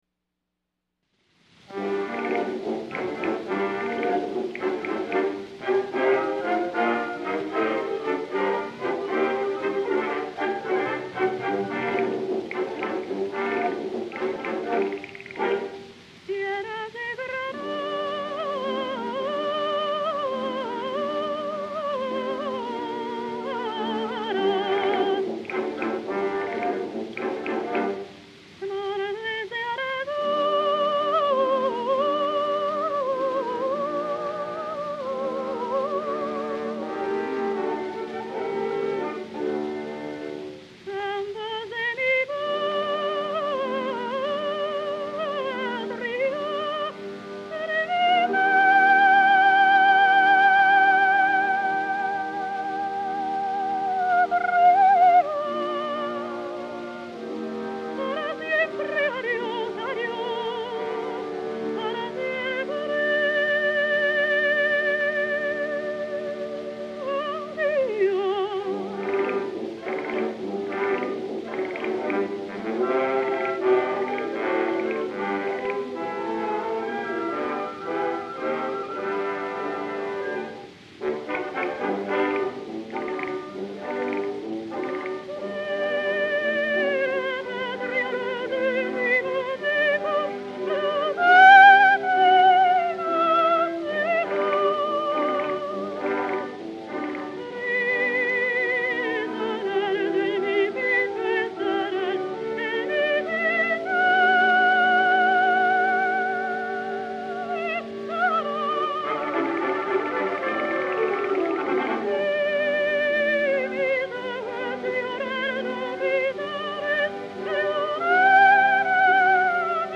Sostituto della penultima ora, ma non da poco, perché tutte o quasi le cantanti coinvolte declinano (molte in chiave di soprano) il mito della grande primadonna con propensione a quella che oggi suole definirsi, con una punta di disprezzo, la baracconata.